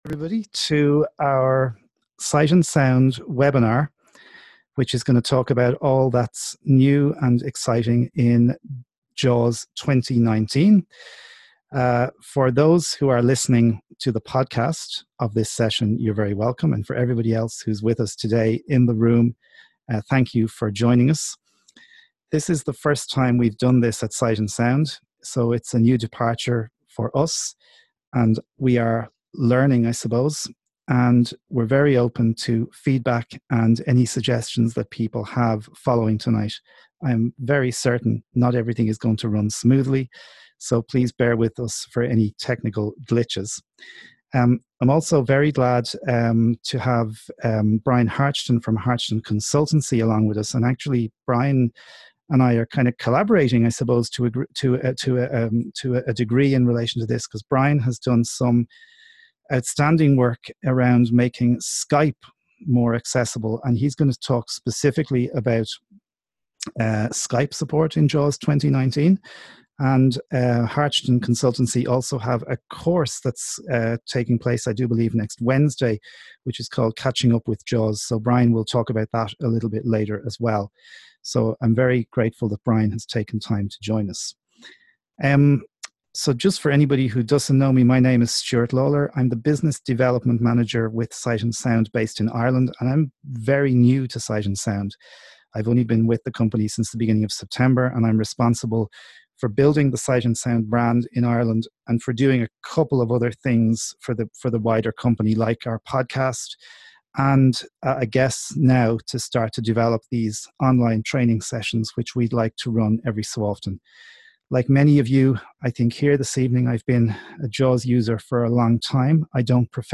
Webinar: All that's new IN JAWS 2019